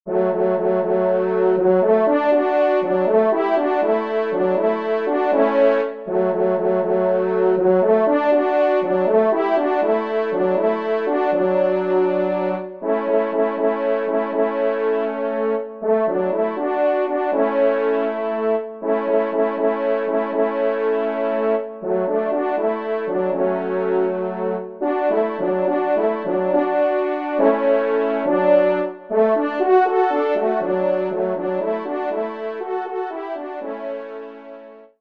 Genre :  Divertissement pour Trompes ou Cors en Ré
2eTrompe